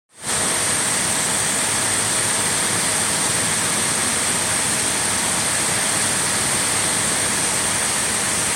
Работа видеокарты MSI R9 270X Gaming 2G ITX при ручной установке 100% оборотов вентилятора.